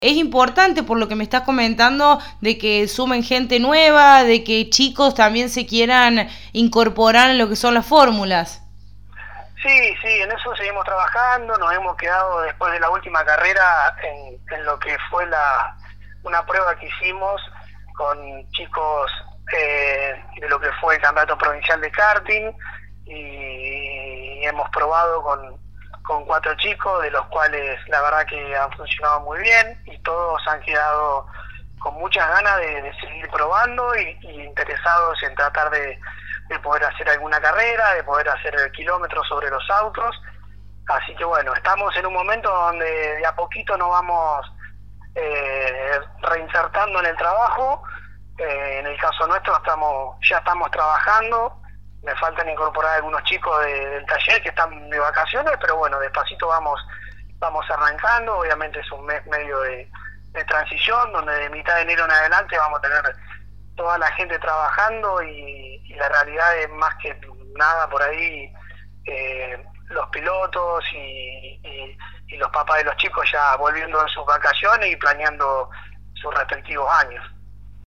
pasó por los micrófonos de Poleman Radio y expresó las expectativas para esta nueva temporada